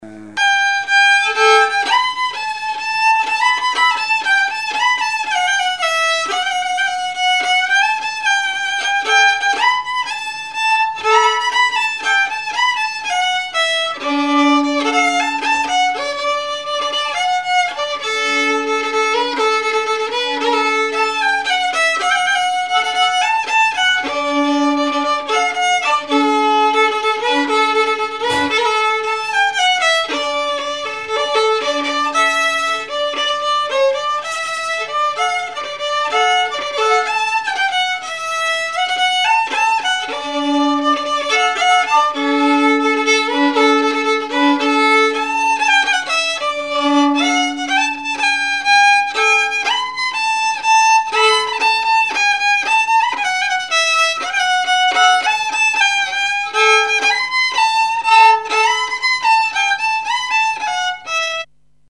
danse : marche
Genre strophique
Témoignages et chansons
Pièce musicale inédite